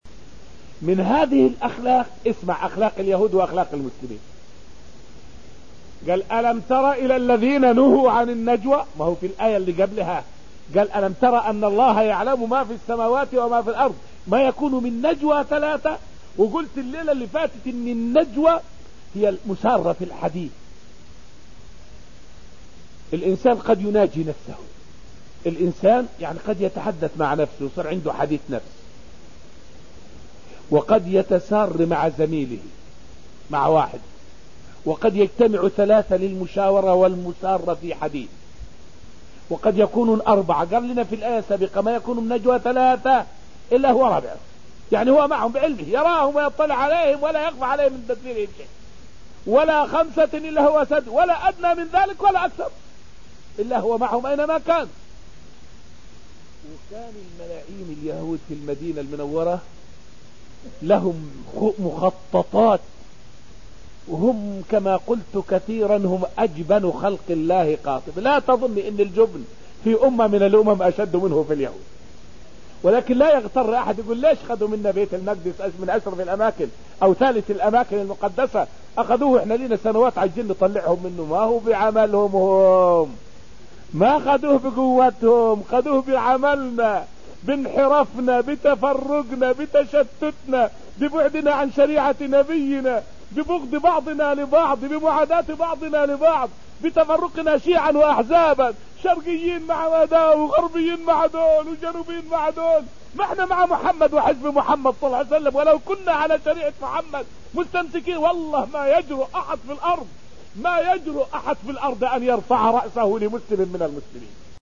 فائدة من الدرس الخامس من دروس تفسير سورة المجادلة والتي ألقيت في المسجد النبوي الشريف حول تفسير قوله تعالى {ألم تر إلى الذين نهوا عن النجوى}.